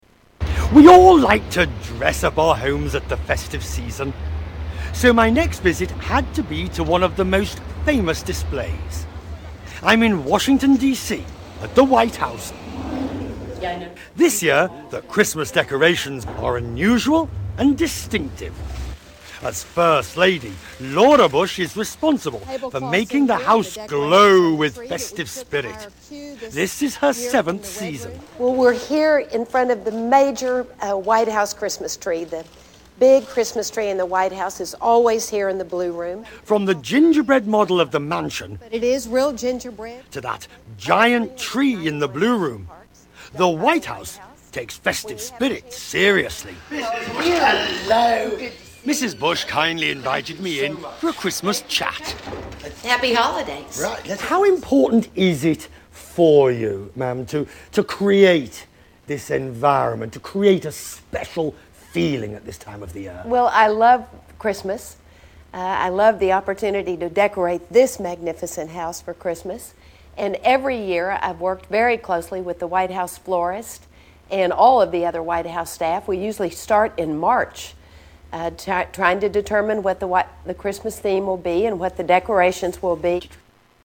Laura Bush Interview